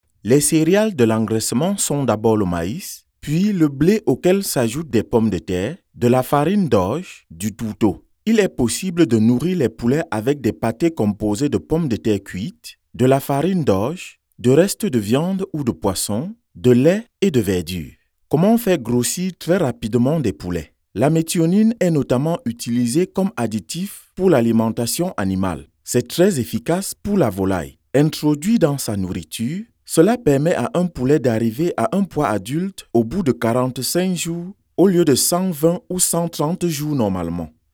BBC tv Voice Over Commercial Actor + Voice Over Jobs
My voice has been described as being young, natural, bright, soft, fresh, conversational and charming with Irish being my native accent.